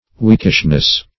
Weakishness \Weak"ish*ness\, n. Quality or state of being weakish.
weakishness.mp3